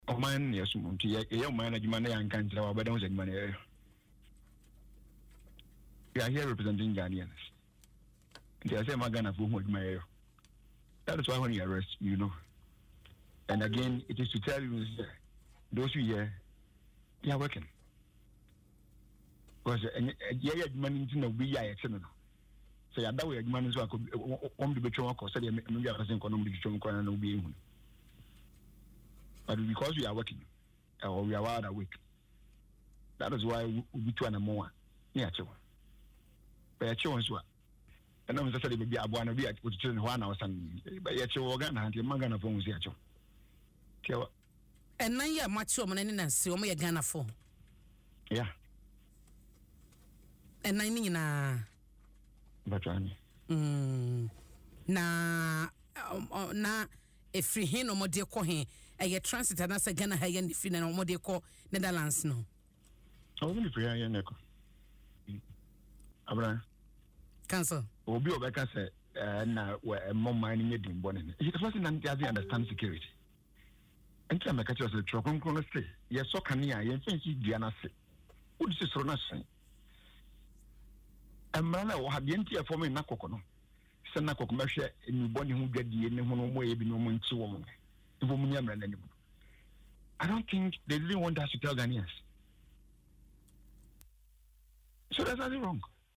In an interview on Adom FM’s Dwaso Nsem, the Deputy Director-General, Alexander Twum Barimah, highlighted the improved oversight and strategic leadership.